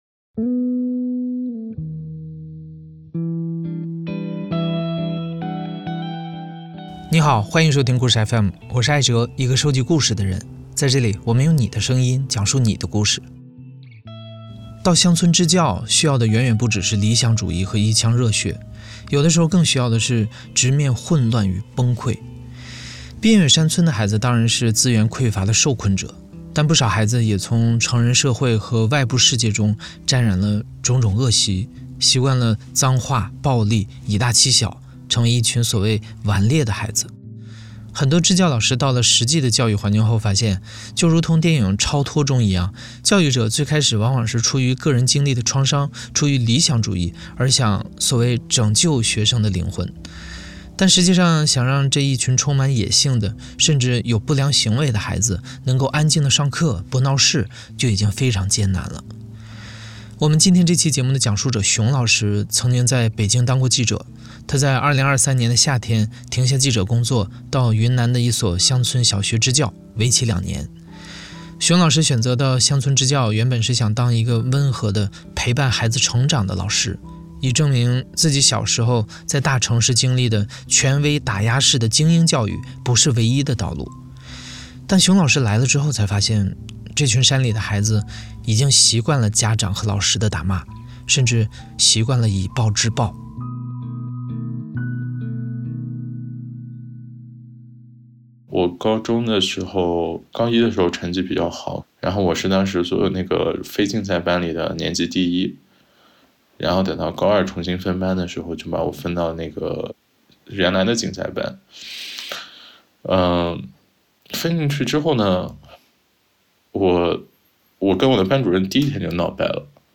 故事FM 是一档亲历者自述的声音节目。